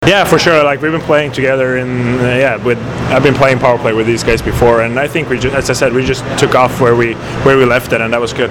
After the game I managed to catch up with Dean Evason, Filip Forsberg, and Scott Darling who gave their thoughts on the game.